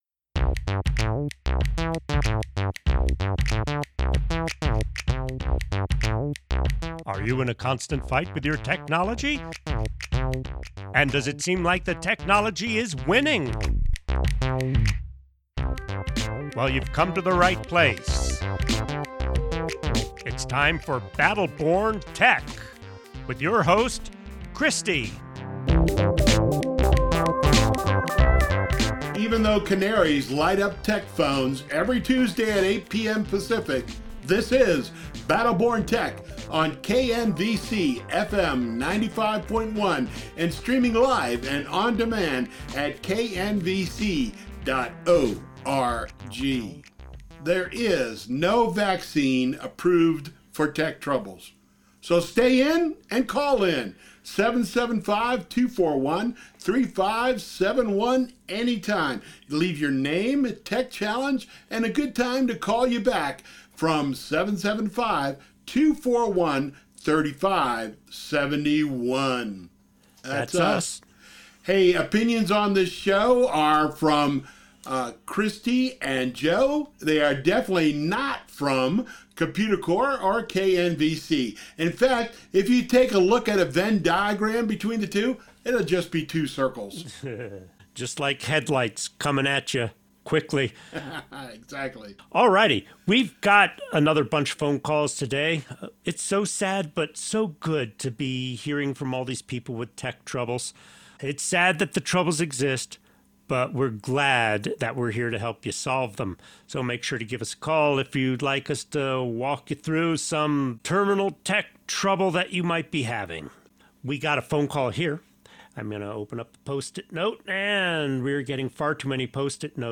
Battle Born Tech KNVC April 6, 2021